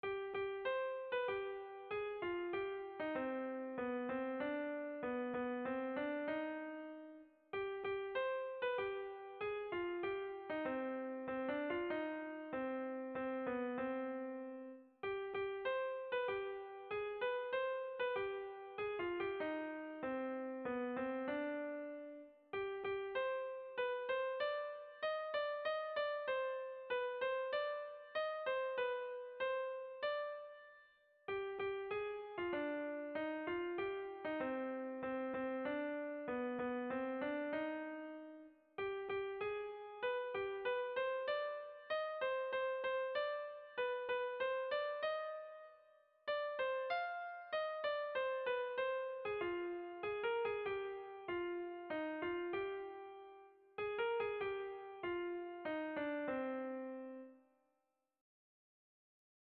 Kontakizunezkoa
Hamarreko handia (hg) / Bost puntuko handia (ip)
A1A2A3BDA4